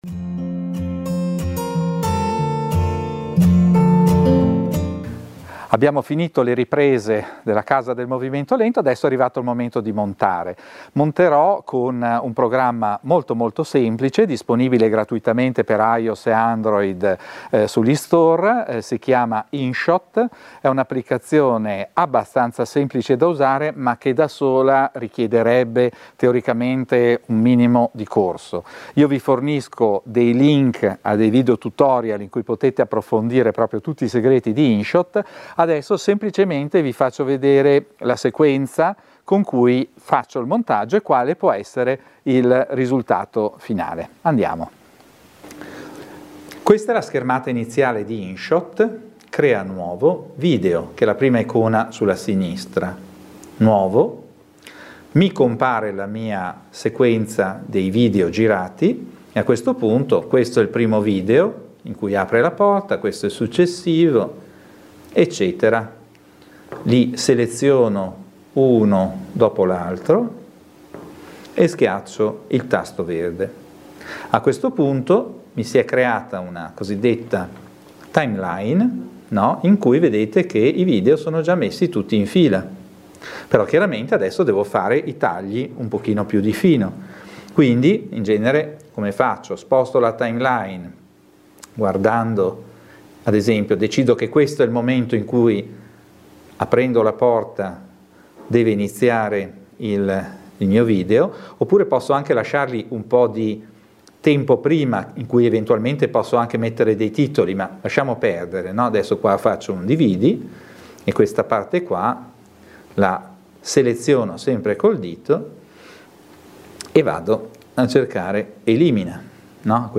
Scarica qui l'audio (mp3) In questa video lezione scoprirai come montare facilmente un video tramite il tuo smartphone. Potrai scaricare l'App di Inshot, sia su Android che su iOs , cercando ''Inshot'' nel Playstore / App Store del tuo smartphone.